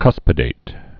(kŭspĭ-dāt) also cus·pi·dat·ed (-dātĭd)